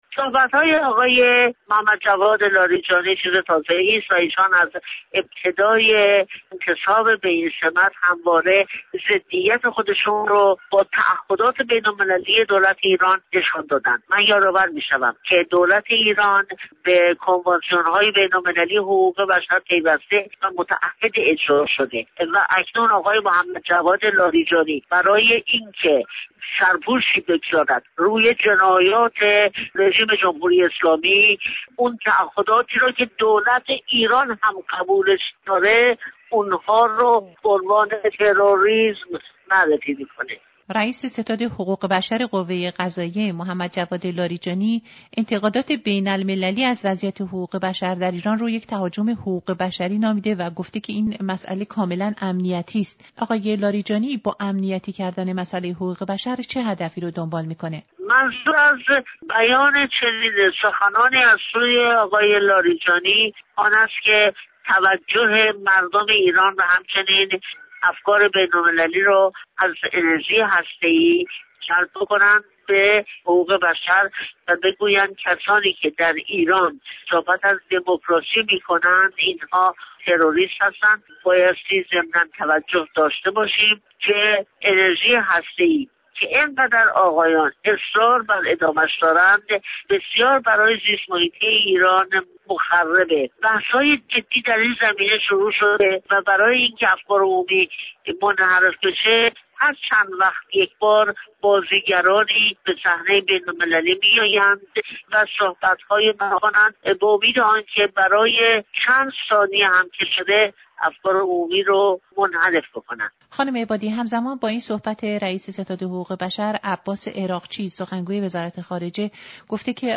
گفت‌وگو با شیرین عبادی درباره اظهارات جواد لاریجانی درباره وضعیت حقوق بشر در ایران